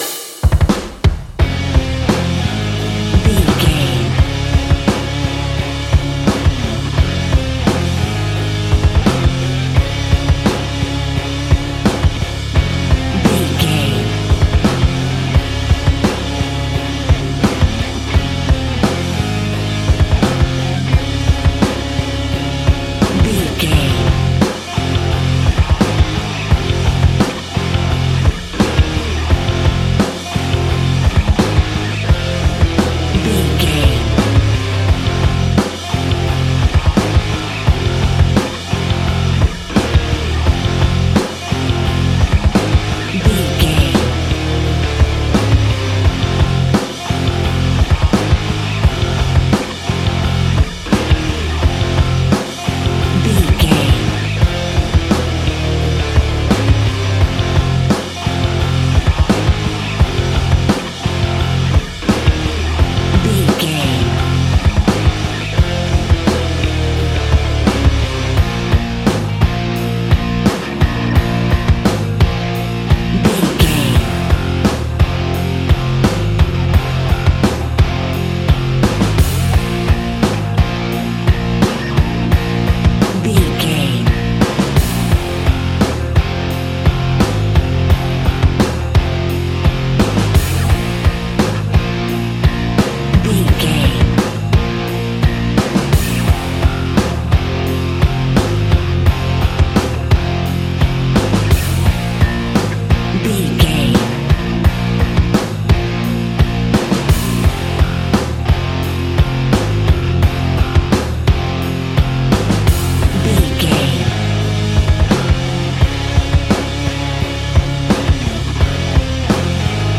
Aeolian/Minor
electric guitar
bass guitar